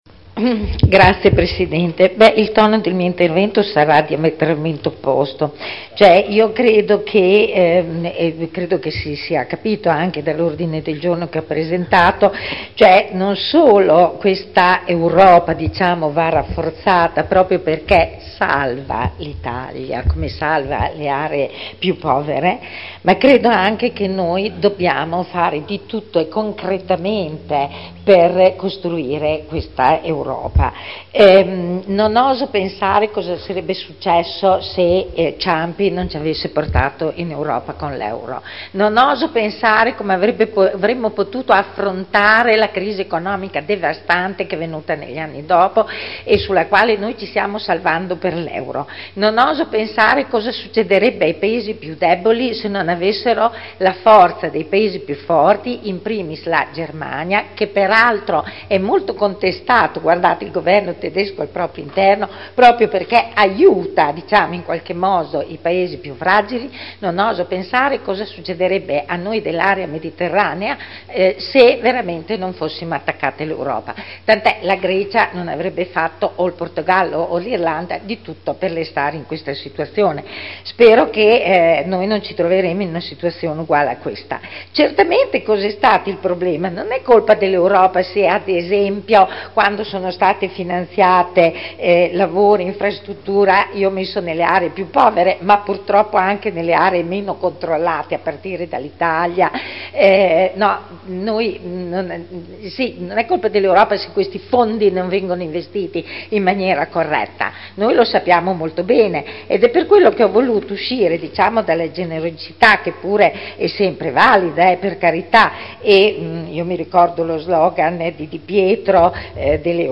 Seduta del 09/05/2011. Dibattito sugli Ordini del Giorno sull'Europa